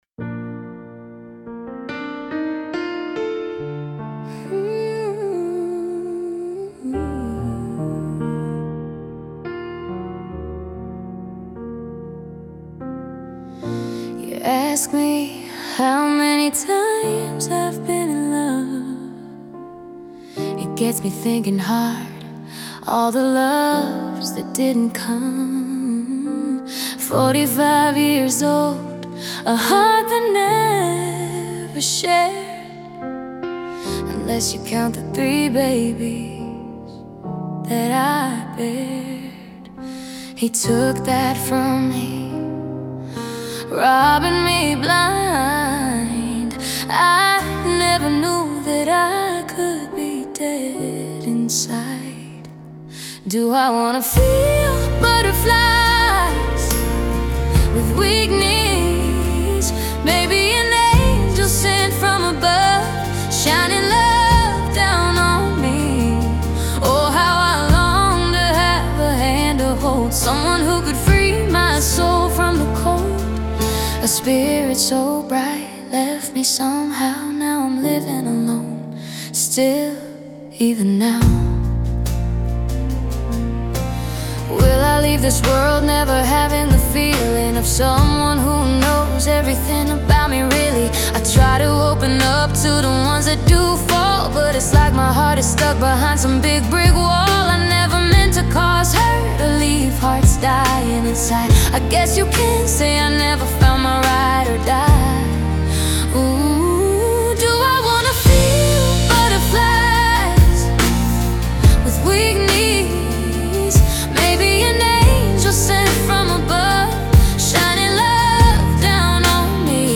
Country , R&B